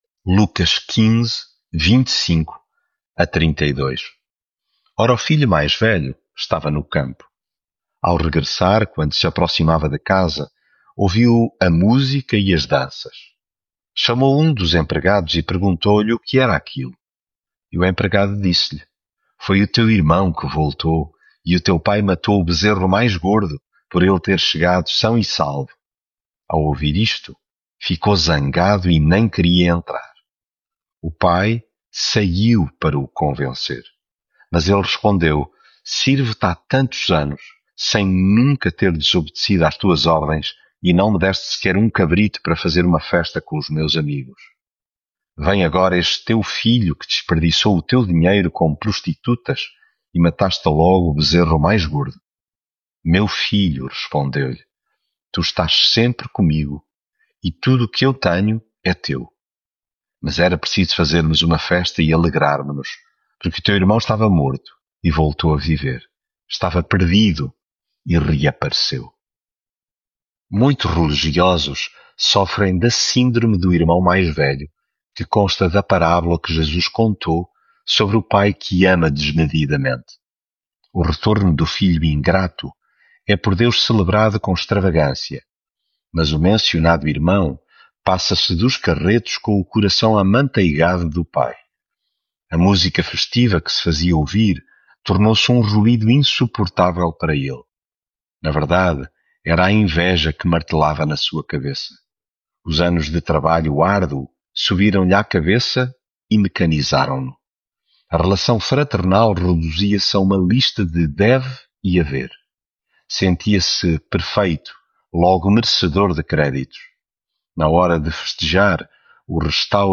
Devocional
leitura bíblica